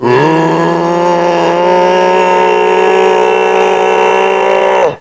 assets/psp/nzportable/nzp/sounds/zombie/r6.wav at 9ea766f1c2ff1baf68fe27859b7e5b52b329afea